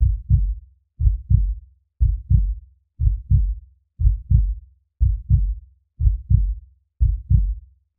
Звук замедленного сердцебия